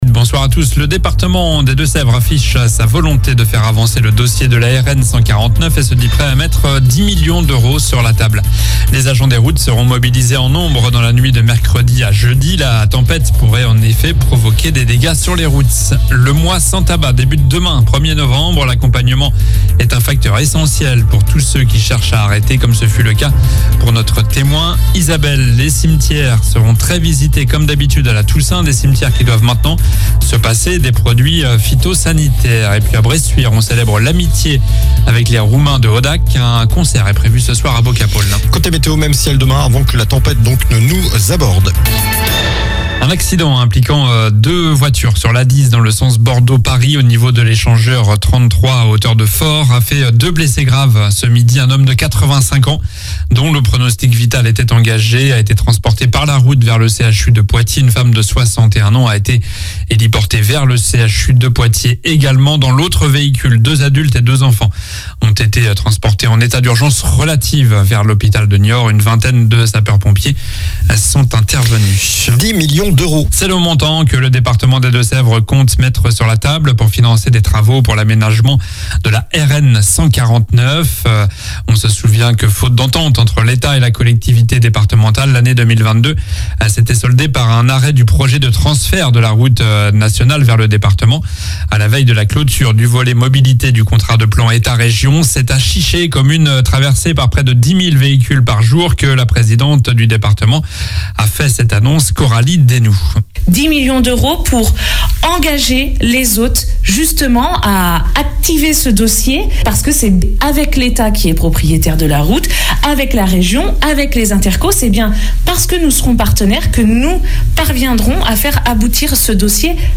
Journal du mardi 31 octobre (soir)